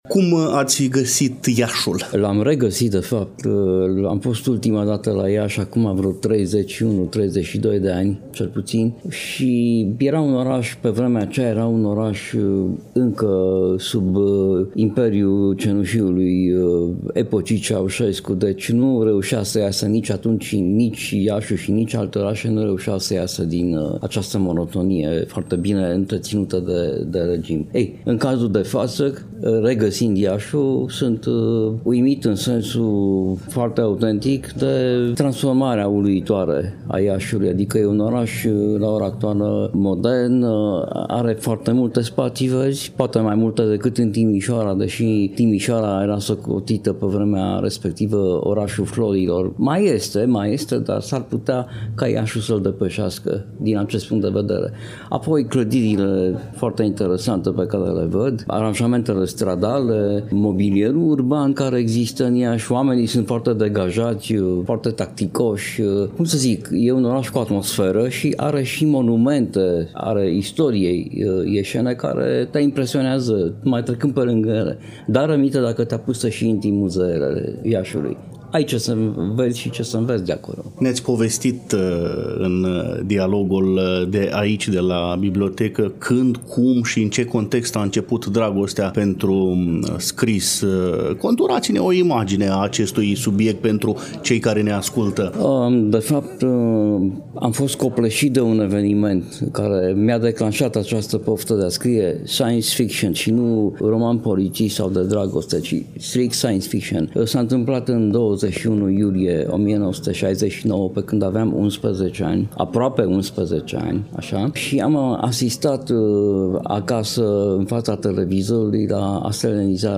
L-am întâlnit, la Iași, la ediția a XII-a, 2024, a Festivalului Internațional de Literatură și Traducere. Secțiunea: Casa Fantasy; Biblioteca Județeană „Gh. Asachi”, Filiala „Ion Creangă”, Casa Sindicatelor.